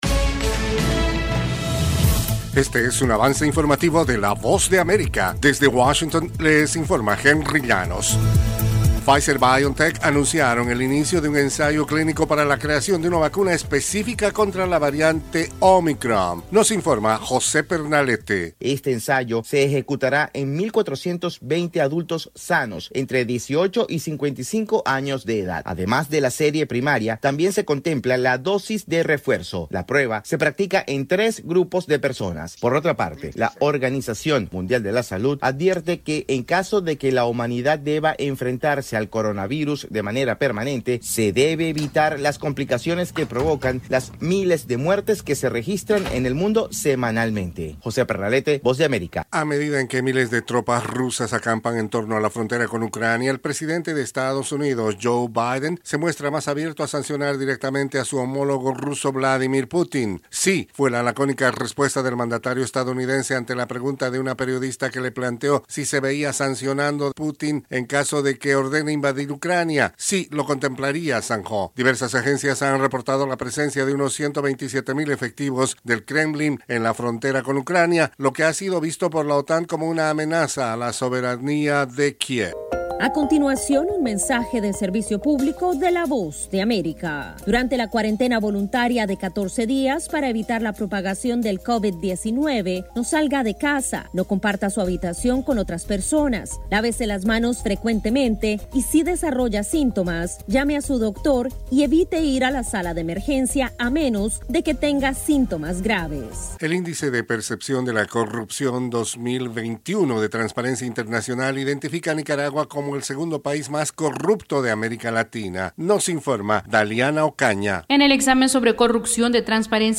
Cápsula informativa de tres minutos con el acontecer noticioso de Estados Unidos y el mundo. [6:00am Hora de Washington].